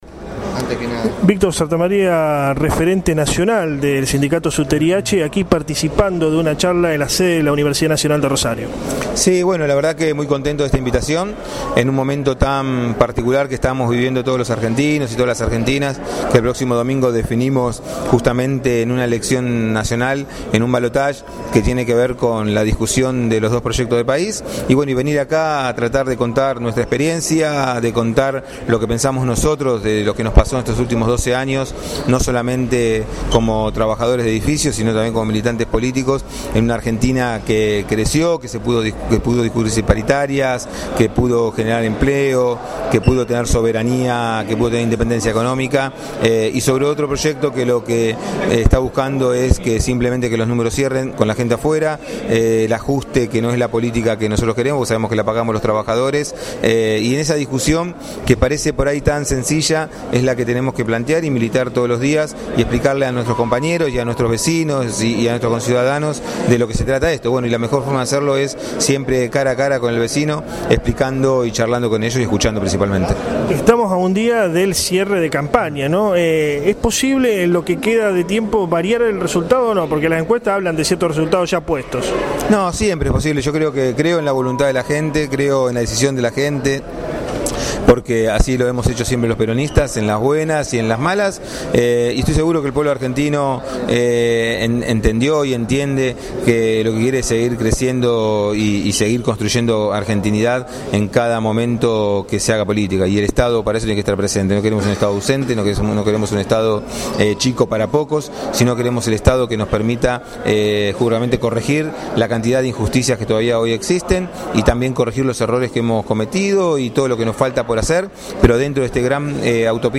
VÍCTOR SANTA MARÍA AUDIO ENTREVISTA